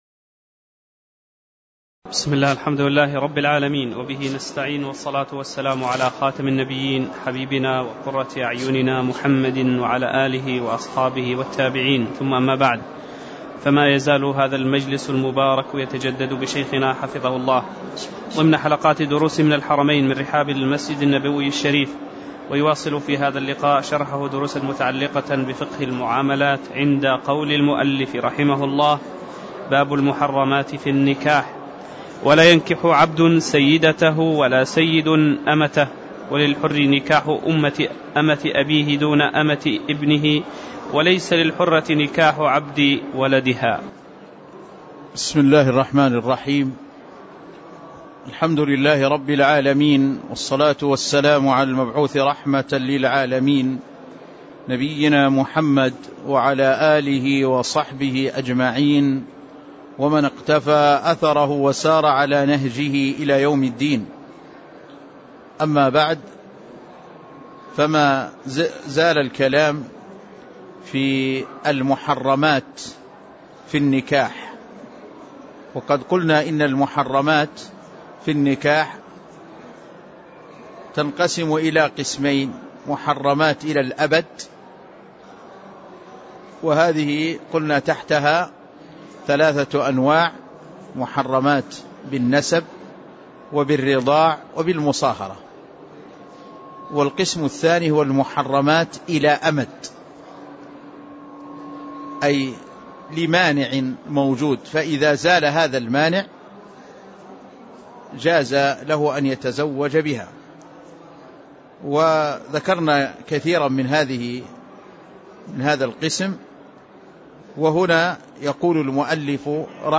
تاريخ النشر ٧ محرم ١٤٣٧ هـ المكان: المسجد النبوي الشيخ